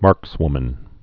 (märkswmən)